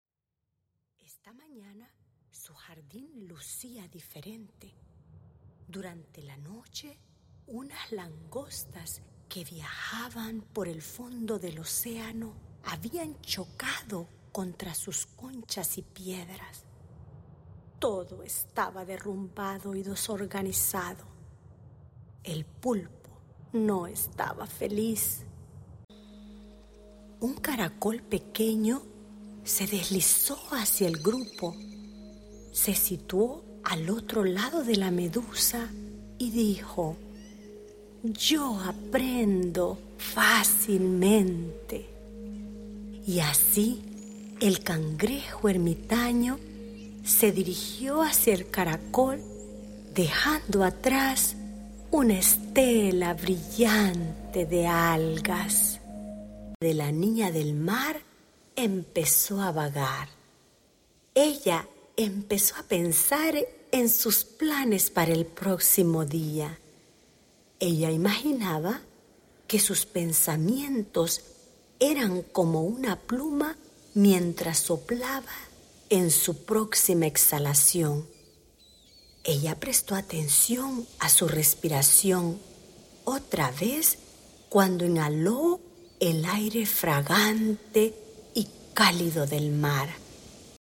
Sueños del Océano Índigo es un libro en CD / audiolibro diseñado para entretener a sus hijos en un ambiente marino, mientras aprenden cuatro técnicas de relajación basadas en la investigación del control del estrés.
Incluimos una pista de música de sonido adicional para mejorar aún más la experiencia relajante de su hijo.